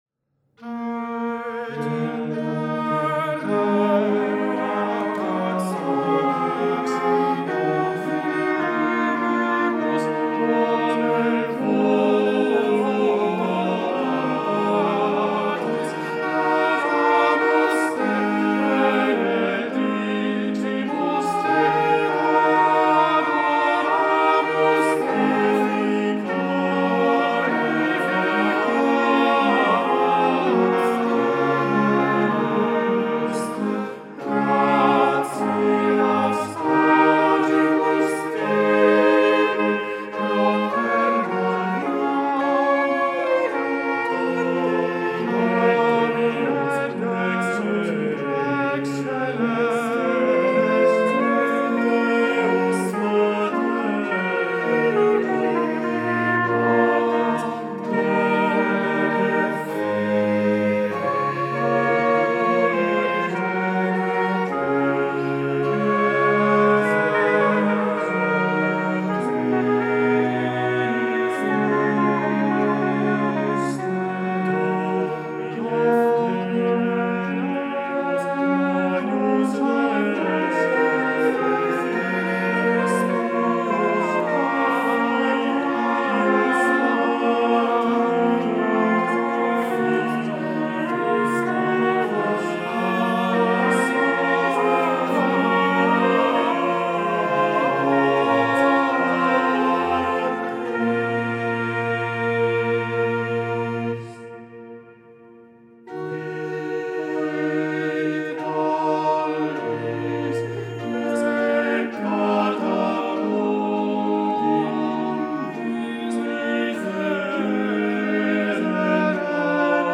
Ensemble Arco Antiqua
Ensemble Arco Antiqua ha presentato una Missa “L’homme Armé” ricca di fascino, con l’uso di strumenti come il cornetto, il trombone, la bombarda e la presenza di ricercate “diminuzioni” , composte grazie all’attento studio dei trattati dell’epoca.
In omaggio all’ambiente mantovano è stato scelto per l’esecuzione un diapason a 466 con temperamento mesotonico 1/4 di comma .